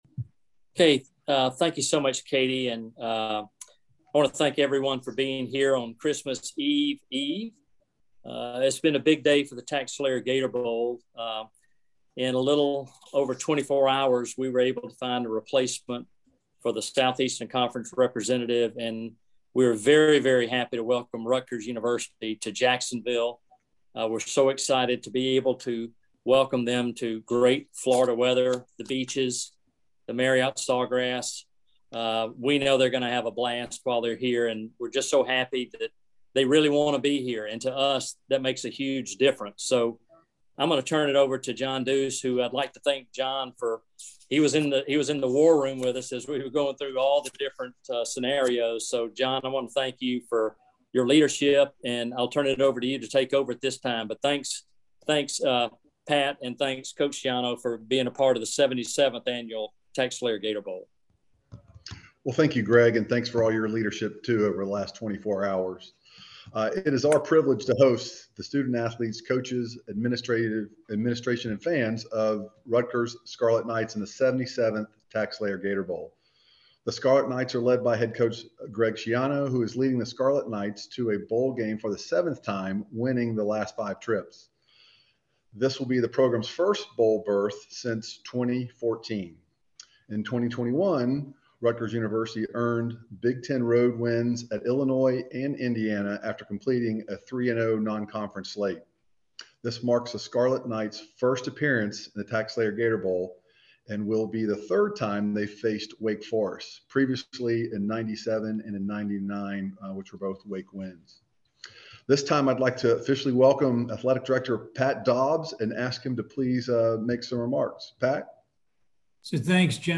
Rutgers Football TaxSlayer Gator Bowl Press Conference - 12/23/21 - Rutgers University Athletics
GATOR_BOWL_PRESSER.mp3